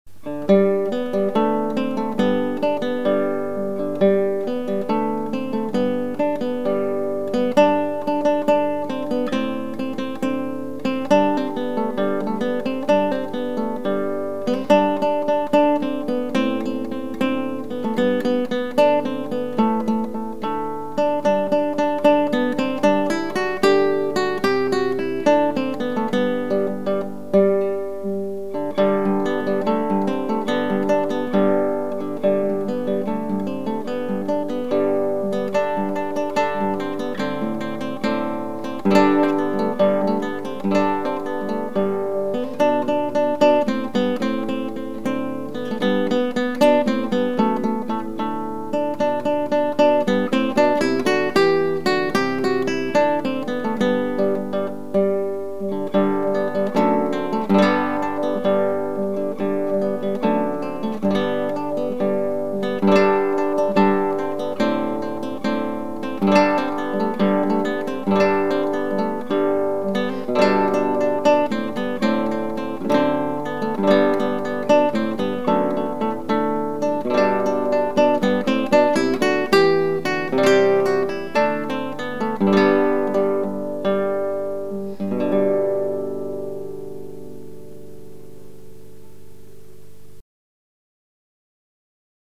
DIGITAL SHEET MUSIC - FINGERPICKING SOLO
Guitar Solo